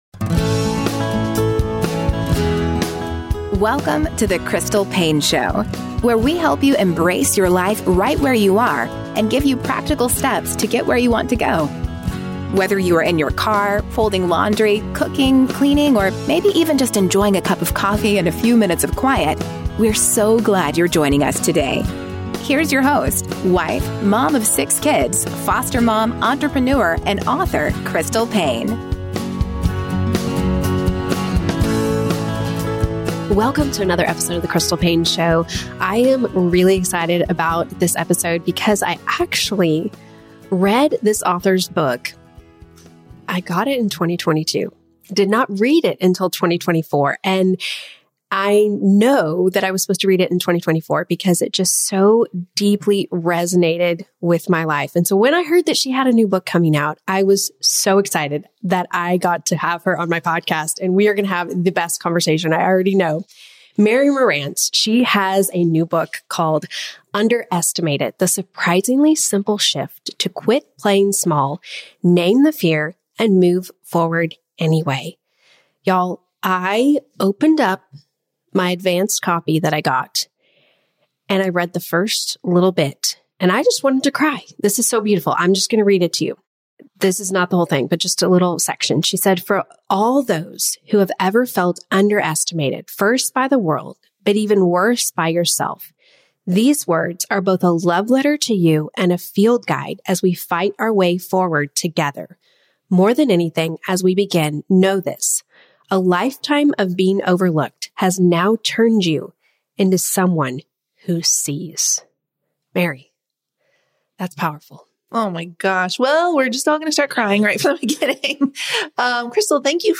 It touched and challenged me deeply... and it wasn't just the interview with her that you'll hear at the link below, but also our time before and after the interview.